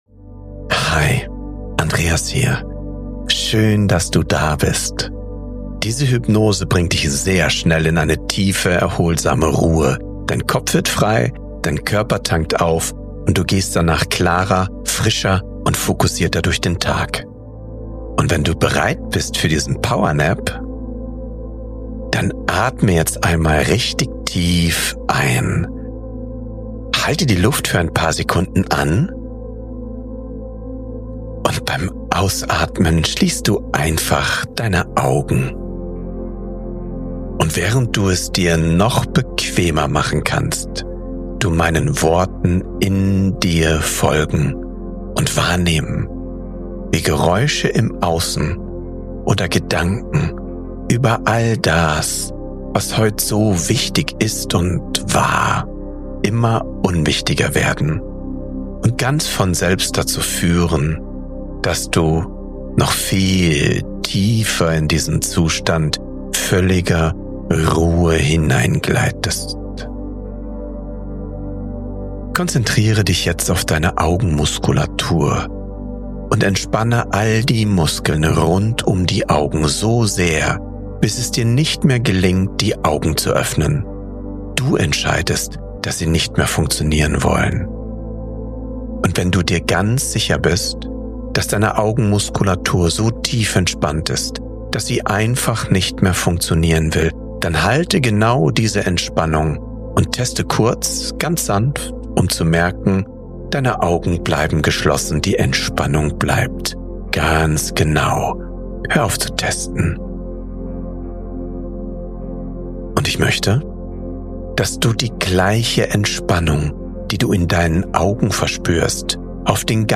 8-Minuten Power Nap – Geführte Hypnose für neue Energie & tiefe Erholung